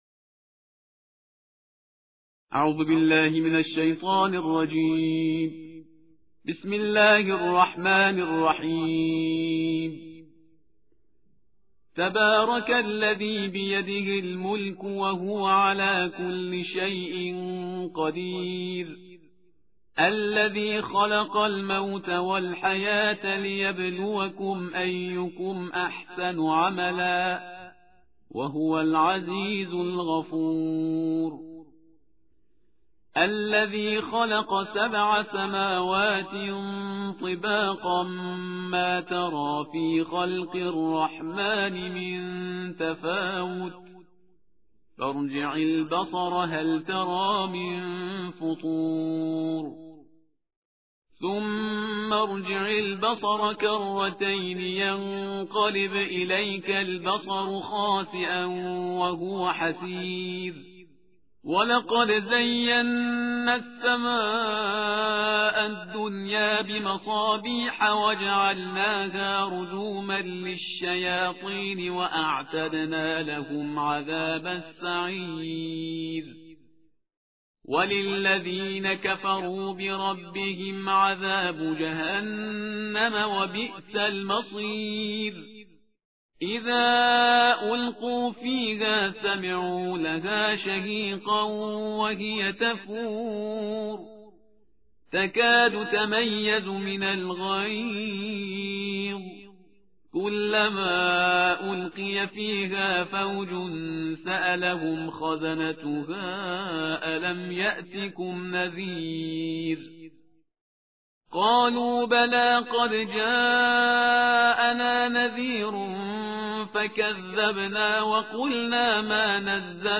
ترتیل جزء «29» قرآن کریم به نیابت از 6300 شهید استان لرستان